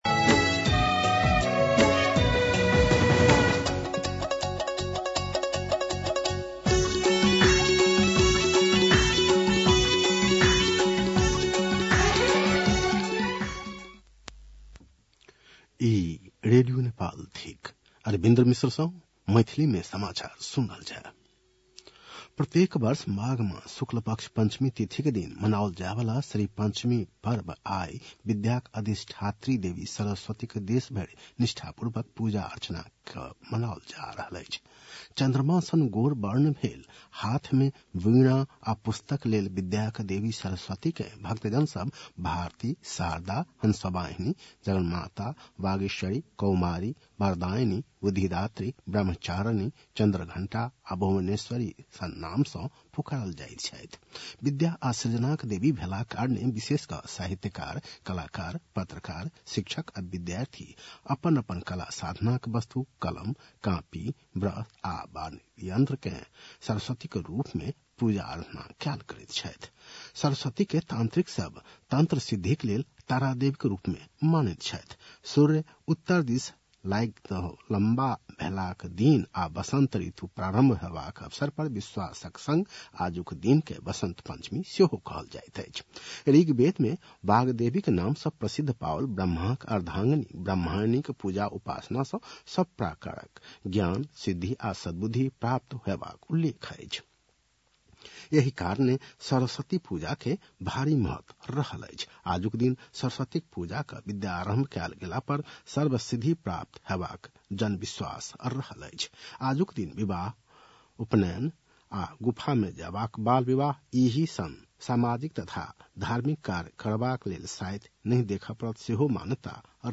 मैथिली भाषामा समाचार : २२ माघ , २०८१
Maithali-News-10-21.mp3